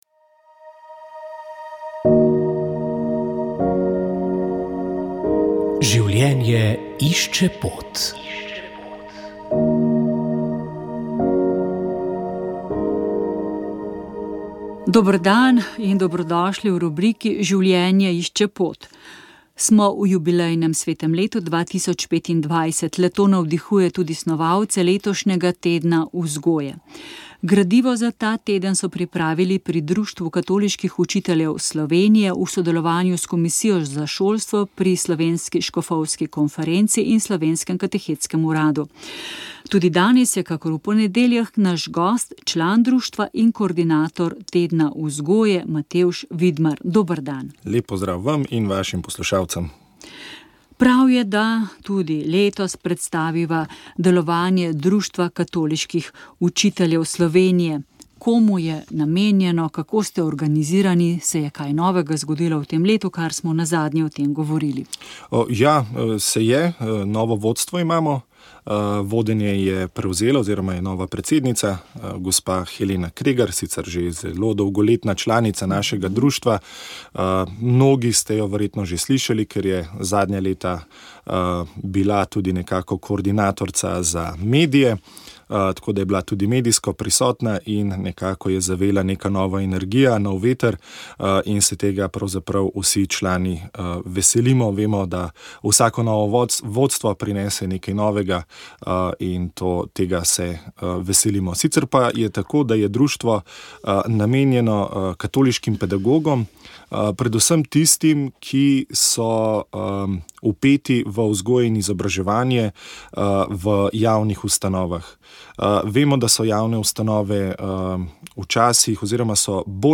V oddaji Moja zgodba lahko v poletnih mesecih spremljate predavanja o koncentracijskem taborišču na Golem otoku, kjer je socialistična oblast prevzgajala sovražnike naroda in države. Inštitut Nove revije je skupaj z Novo univerzo novembra 2019 pripravil celodnevno kritično razpravo o Golem otoku po sedemdesetih letih od njegove ustanovitve.